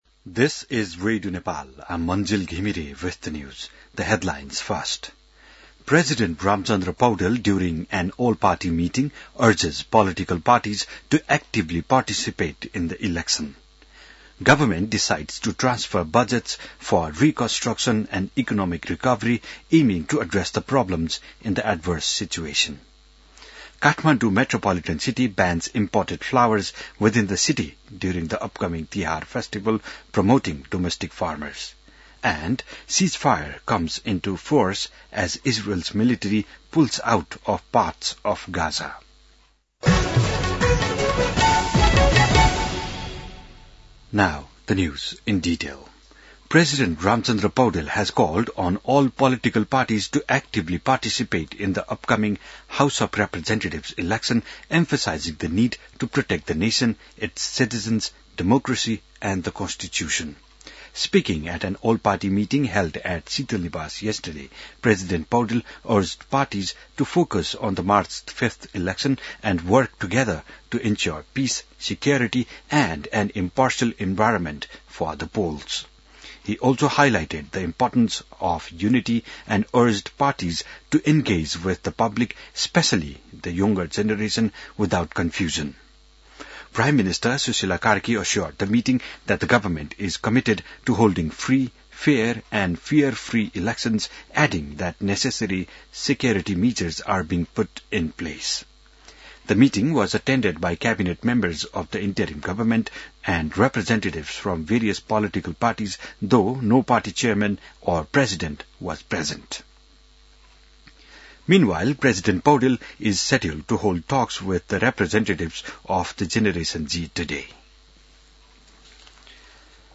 An online outlet of Nepal's national radio broadcaster
बिहान ८ बजेको अङ्ग्रेजी समाचार : २५ असोज , २०८२